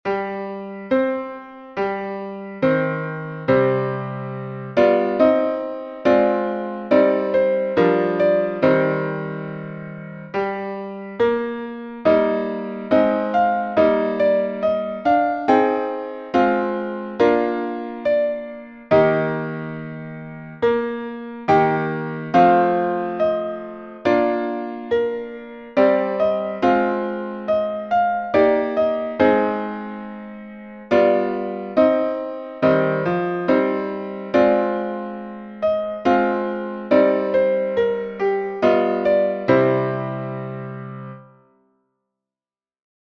Pategian_anthem.mp3